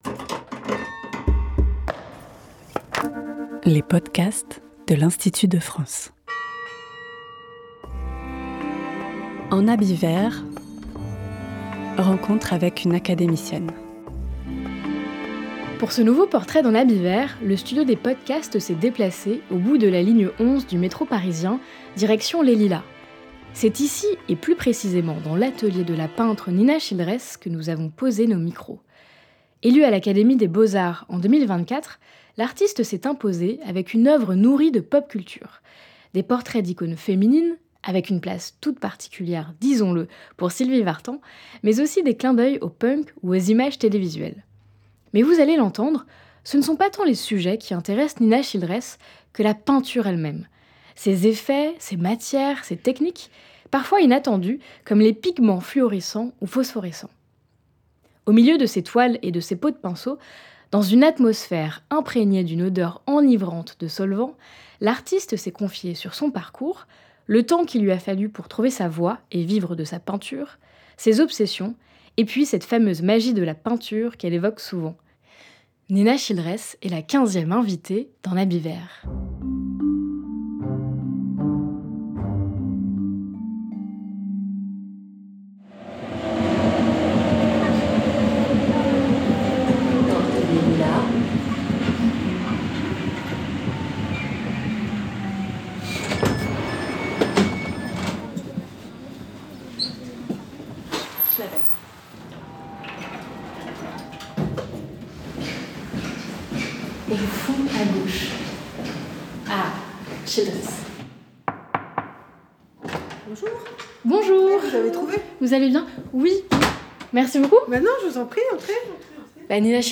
Pour ce nouveau portrait d’En habit vert, le studio des podcasts s’est délocalisé à l’est de la ligne 11 du métro parisien, en direction des Lilas.